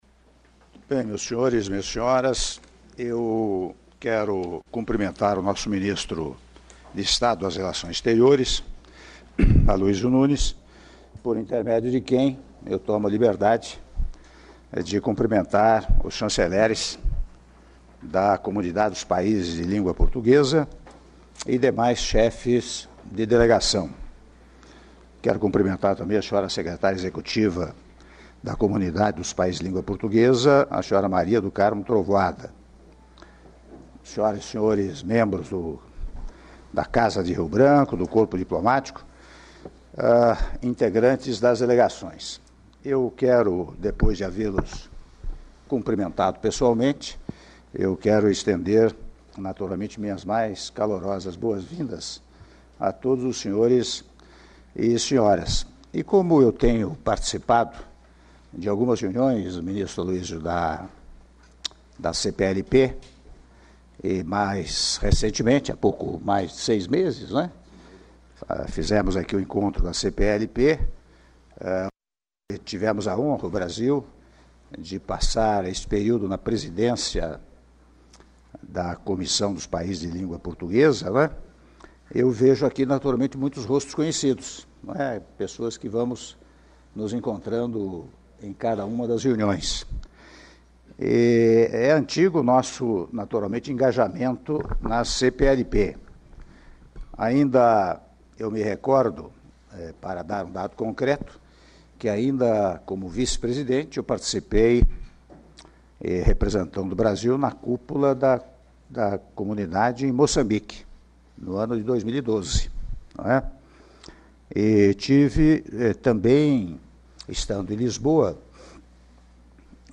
Áudio do discurso do Presidente da República, Michel Temer, durante a XXII Reunião Ordinária do Conselho de Ministros da Comunidade dos Países de Língua Portuguesa (CPLP) - Brasília/DF- (07min19s)